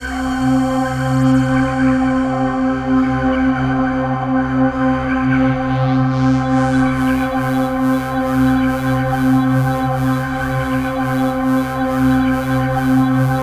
Index of /90_sSampleCDs/USB Soundscan vol.13 - Ethereal Atmosphere [AKAI] 1CD/Partition C/04-COBRA PAD